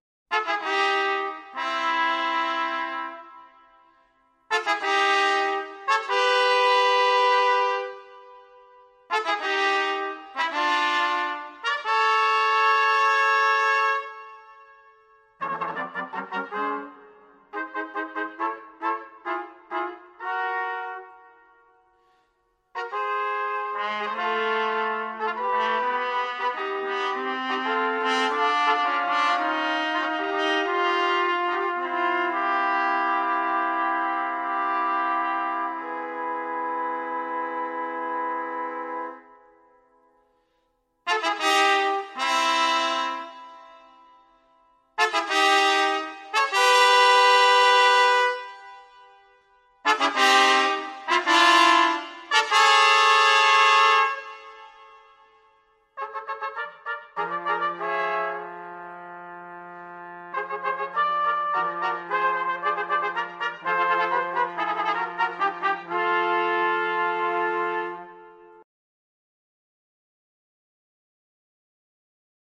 quintette de trompettes (09'50)
** Studio BOLO.